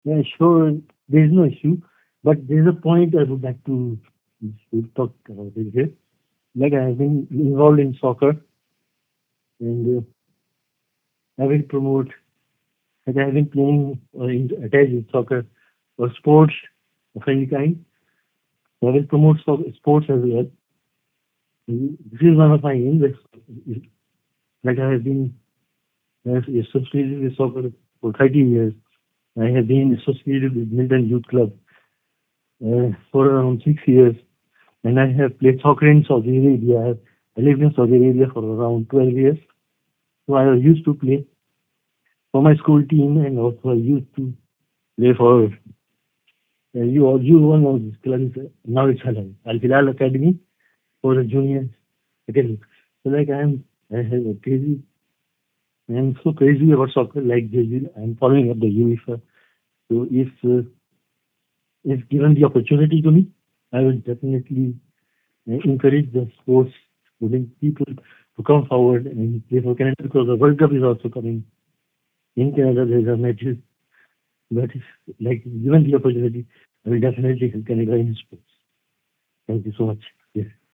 He was available by phone for this interview.